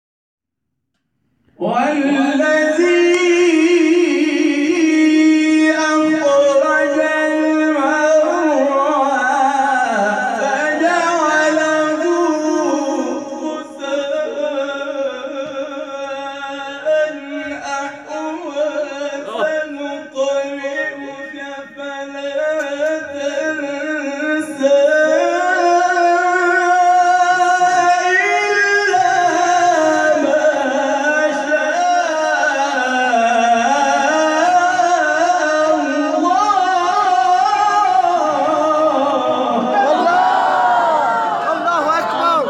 شبکه اجتماعی: مقاطعی صوتی از تلاوت قاریان ممتاز کشور را می‌شنوید.